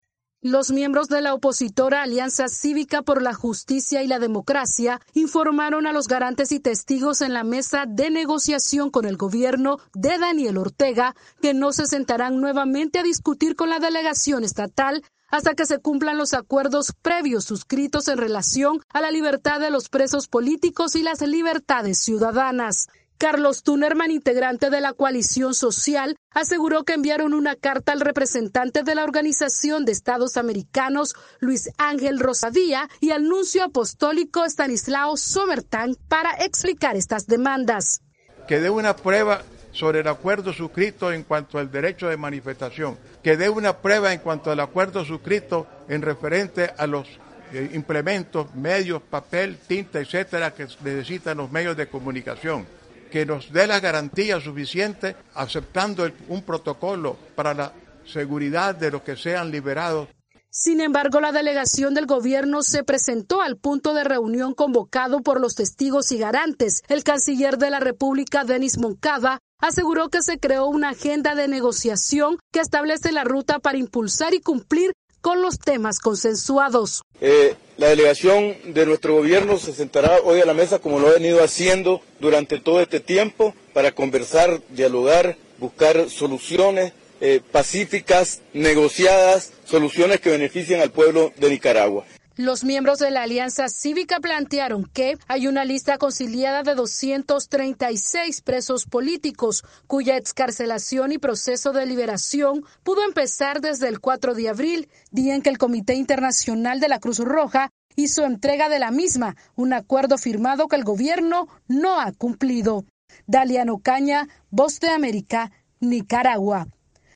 VOA: Informe desde Nicaragua